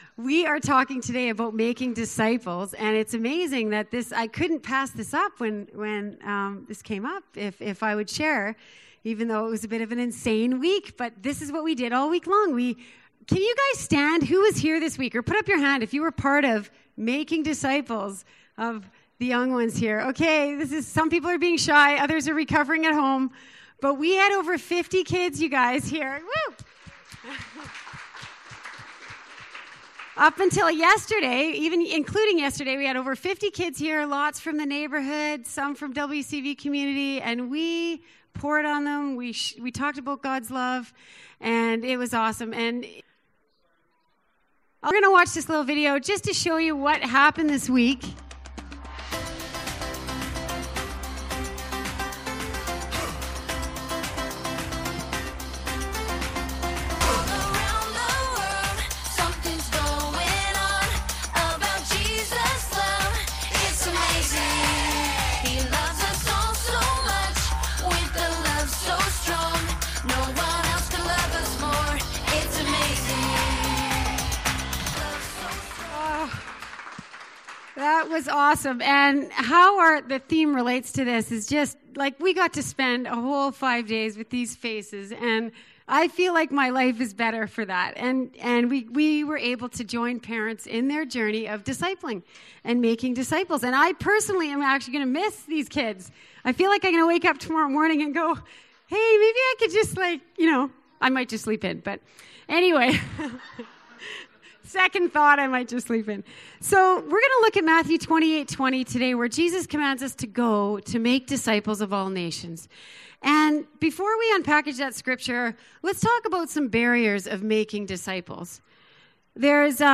Two friends from the Colorado team also share their stories of discipleship in their own lives.
Service Type: Downstairs Gathering